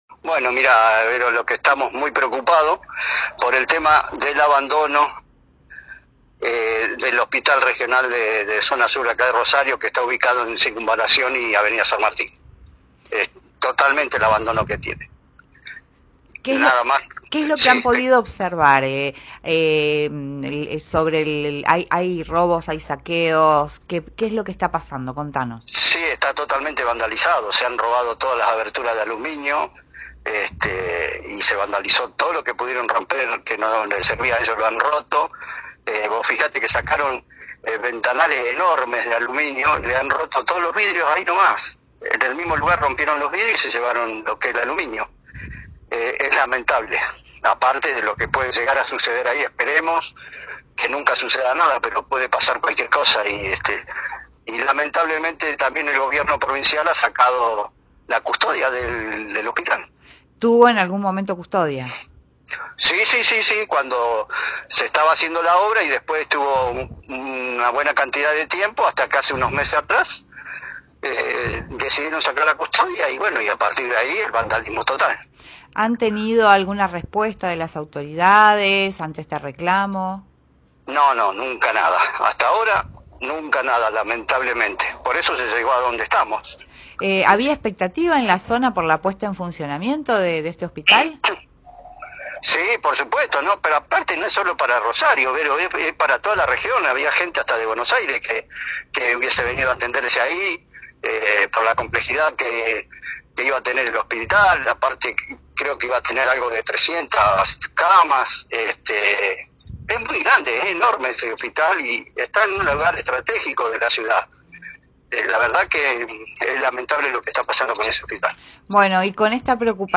dialogó con Primera Plana de Cadena 3 Rosario y mostró su preocupación “por el abandono del Hospital Regional Zona Sur en Rosario”.